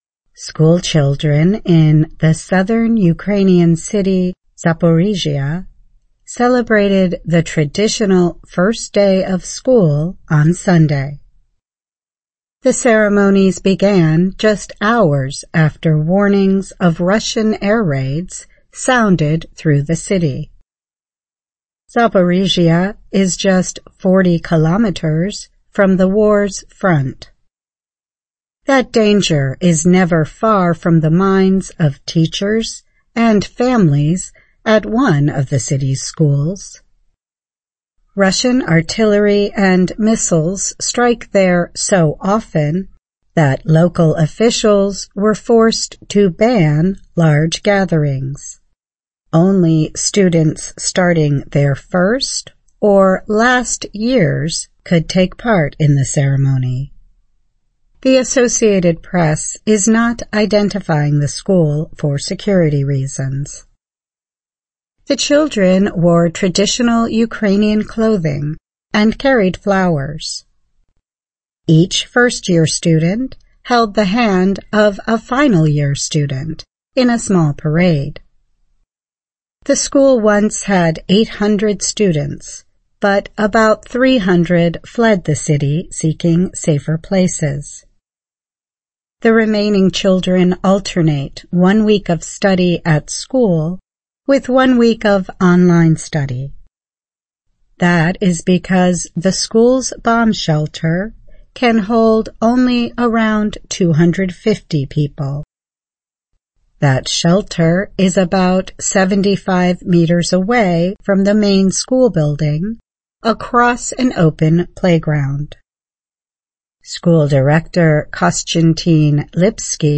2024-09-04 [Education Report] Ukraine's Children Celebrate First Day of School, But Missiles Still Fly
VOA慢速英语逐行复读精听提高英语听力水平